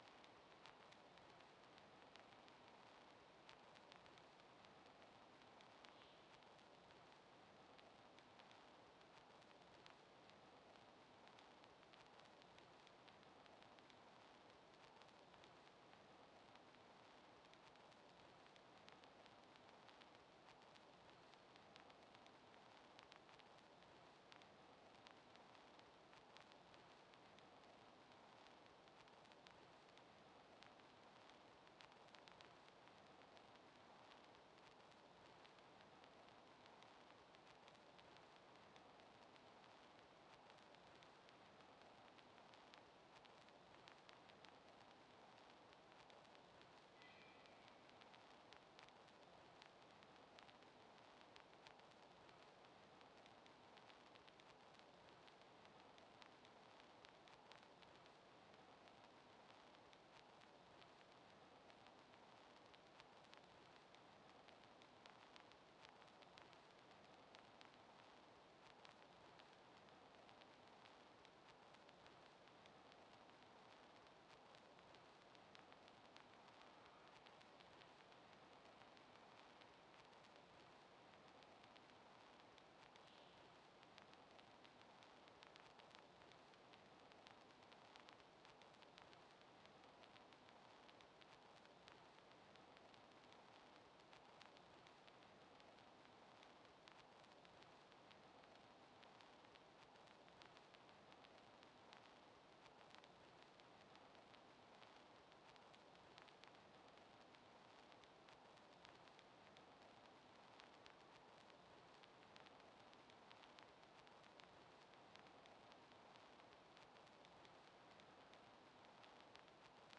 This is a recording of a very quiet ambience which has been processed in post-production to convey the effect of old vinyl, and the cellulose records of the 1930s. This is to create a relationship between the soundtrack and the visual film material of Bathing & Dressing, Parts 1 & 2.